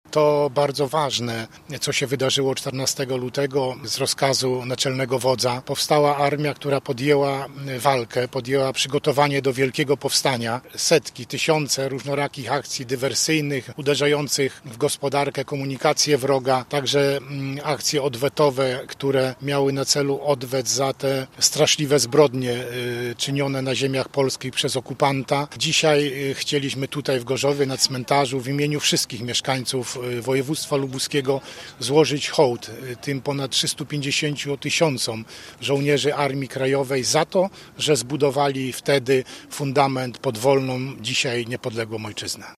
Dziś oddano im hołd na cmentarzu komunalnym w Gorzowie. Pod Pomnikiem Pamięci Armii Krajowej kwiaty złożyli przedstawiciele służb mundurowych, władz miasta i wojewoda lubuski Władysław Dajczak: